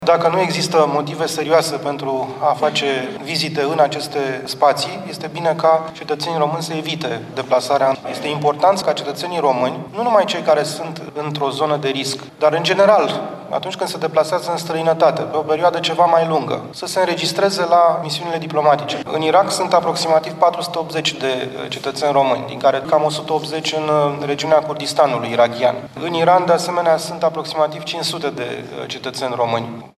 Audiat astăzi la Parlament, în comisiile reunite de Apărare și Afaceri Externe pe situația din Orientul Mijlociu, ministrul de Externe, Bogdan Aurescu a precizat că niciun român din cei care locuiesc în această regiune nu a cerut până acum să fie repatriat: